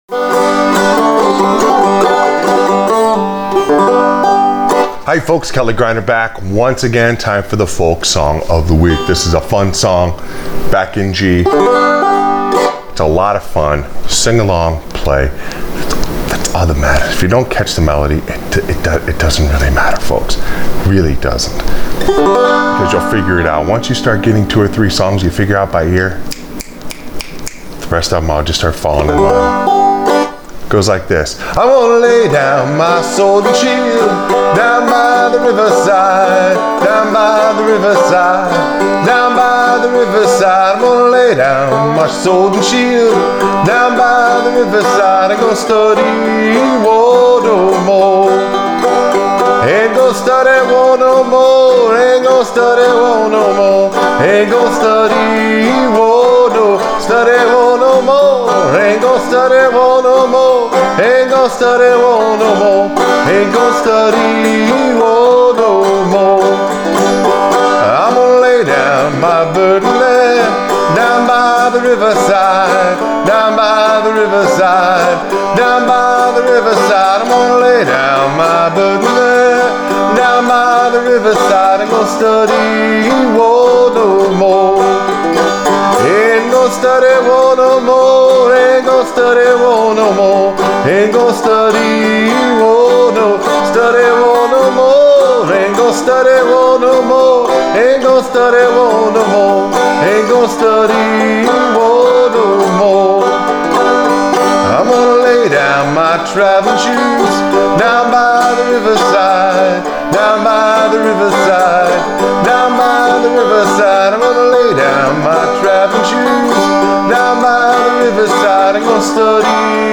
Folk Song Of The Week – Down By The Riverside on Frailing Banjo